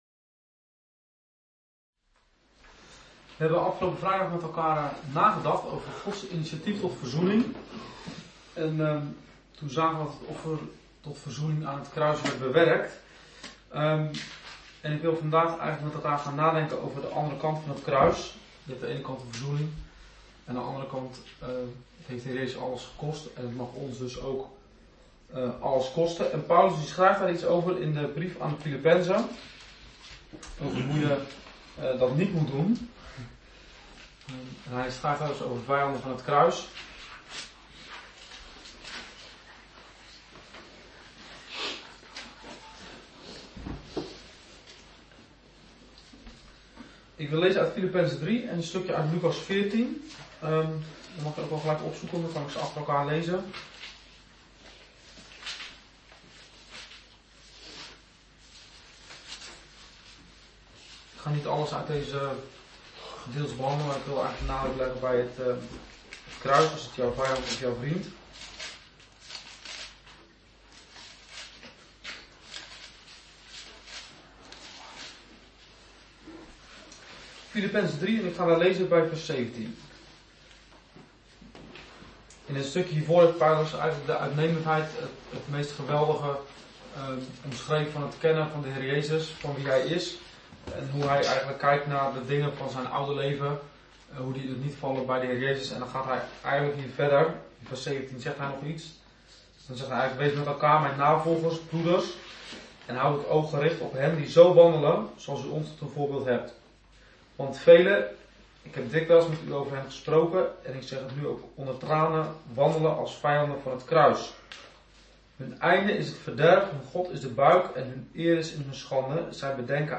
Een preek over 'Het kruis van Jezus... jouw vijand of vriend?'.